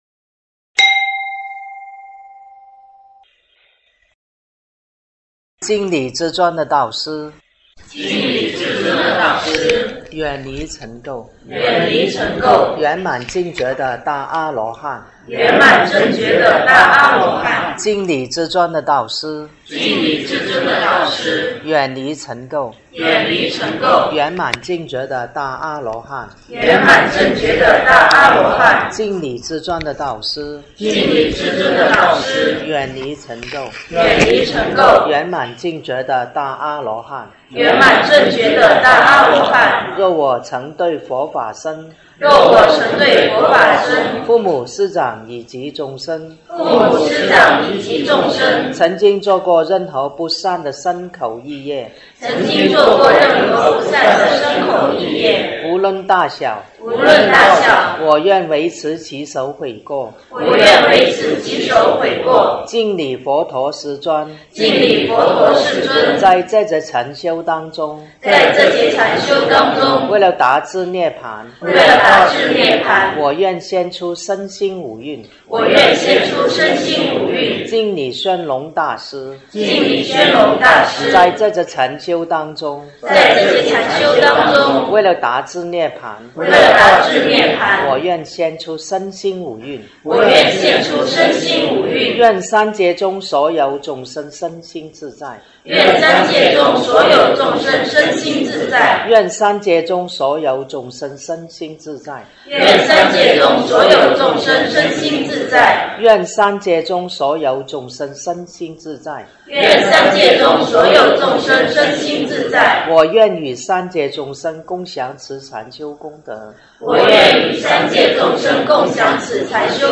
90分钟禅修(5分钟木鱼+1小时引磬版).MP3